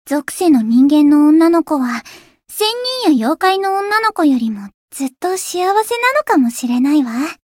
灵魂潮汐-敖绫-七夕（送礼语音）.ogg